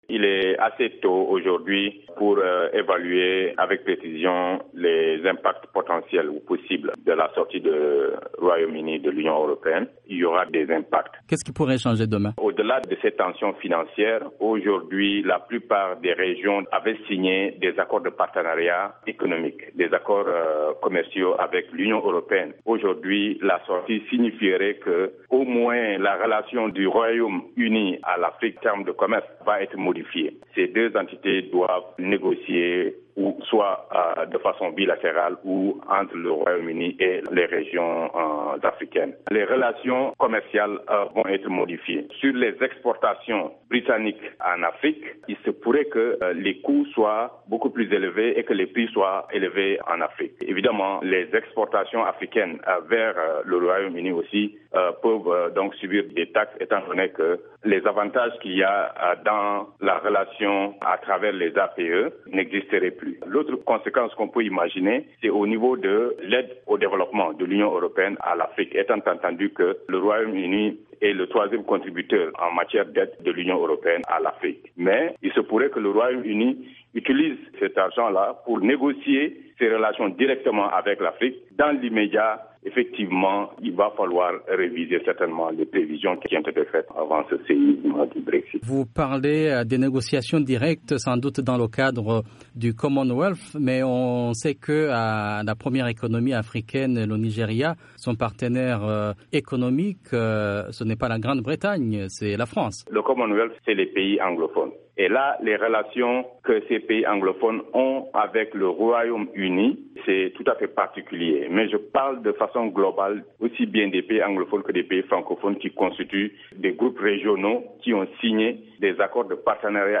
Dans un entretien accordé à VOA Afrique